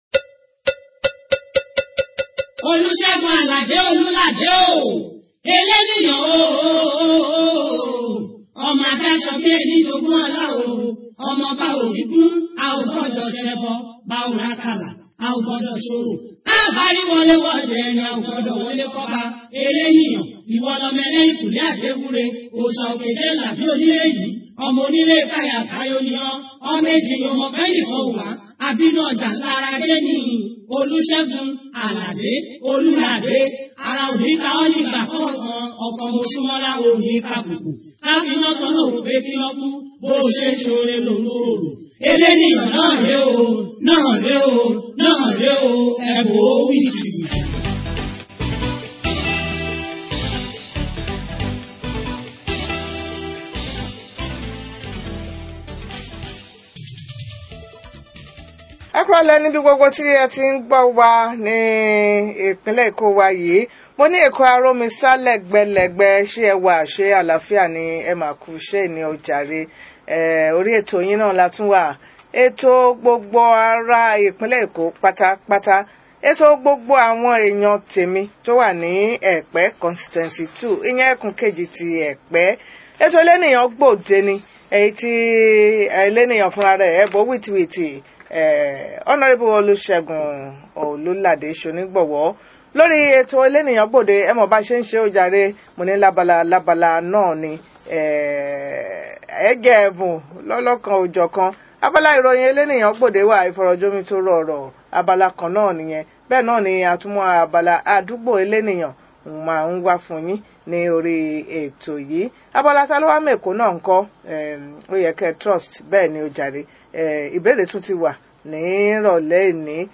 Guest: Hon. Segun Olulade ‘Eleniyan’ – Epe Constituencey II
Venue: Radio Lagos 107.5 FM